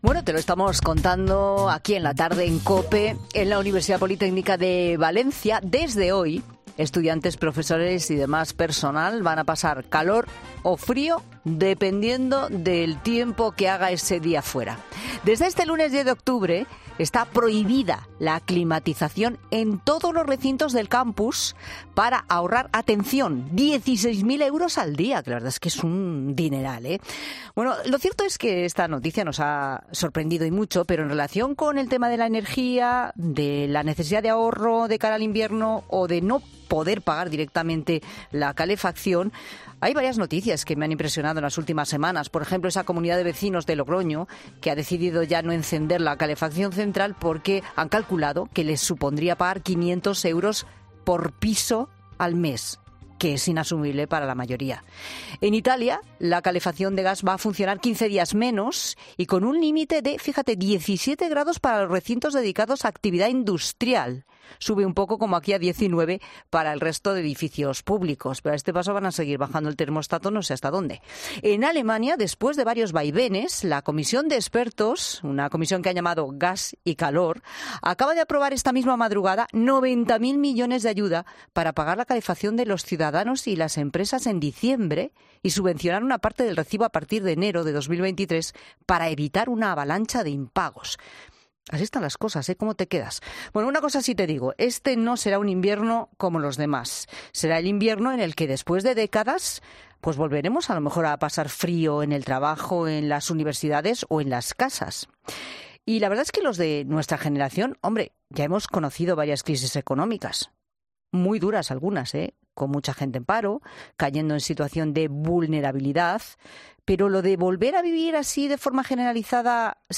El economista Fernando Trías de Bes ha pasado por los micrófonos de La Tarde de COPE para aclarar si las empresas privadas seguirán los pasos de la Universidad Politécnica de Valencia y cortarán la calefacción este invierno .